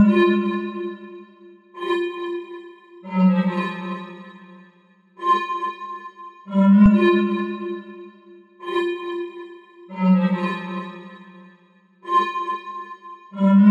不祥的长笛警报声刺耳
标签： 140 bpm Trap Loops Flute Loops 2.31 MB wav Key : Unknown FL Studio
声道立体声